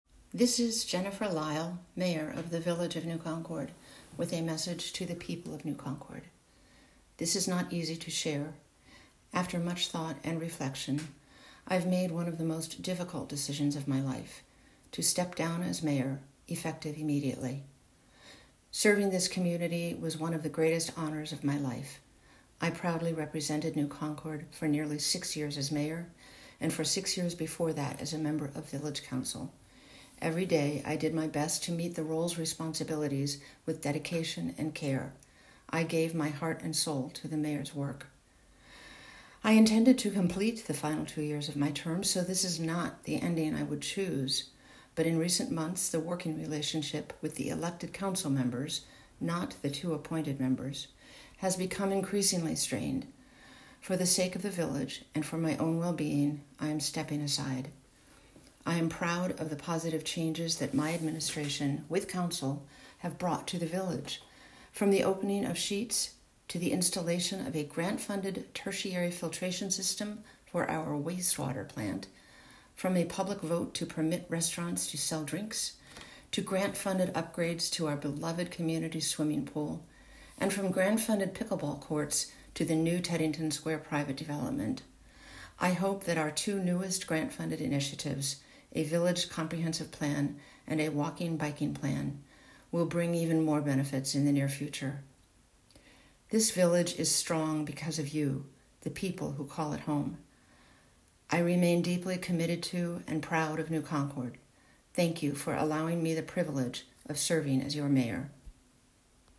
Village of New Concord Mayor Lyle Resignation Statement